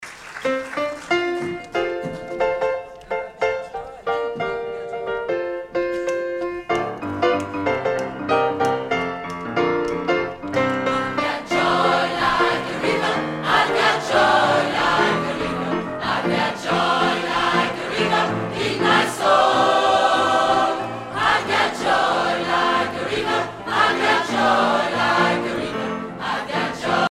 Edition discographique Live
Pièce musicale éditée